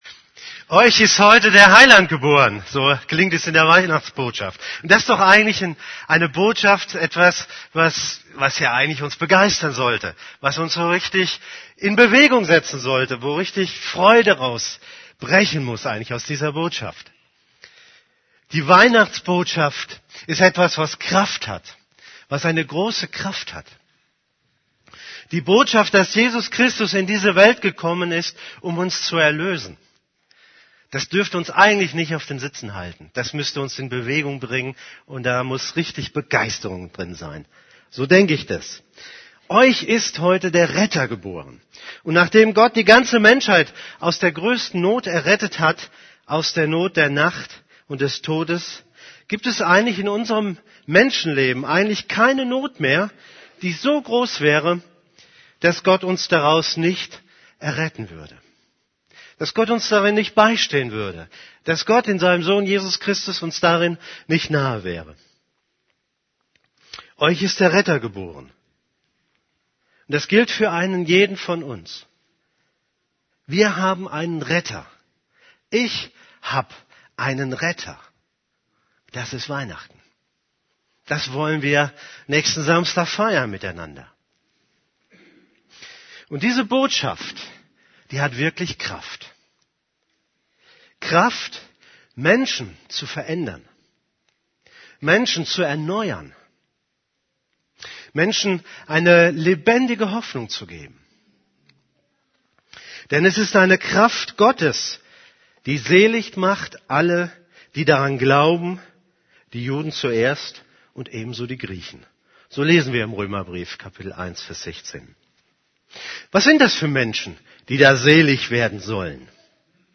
> Übersicht Predigten Der Weihnachtsauftrag Predigt vom 18.